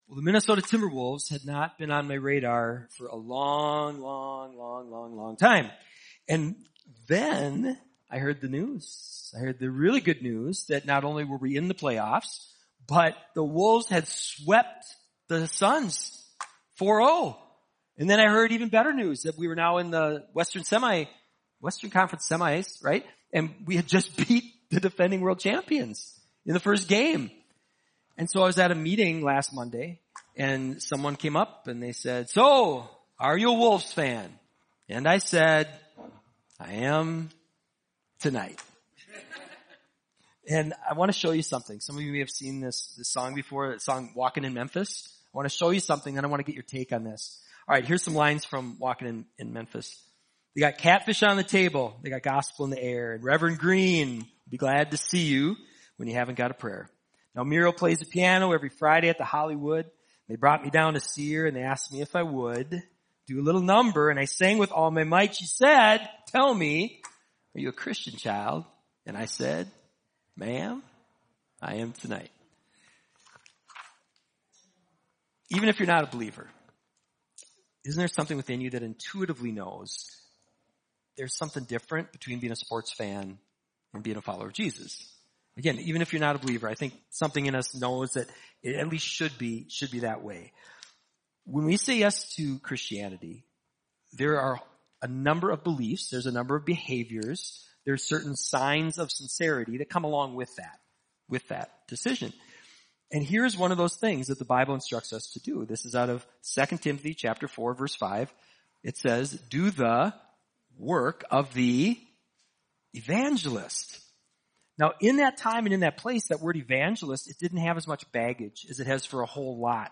Good News to Share Pathways Watch Message By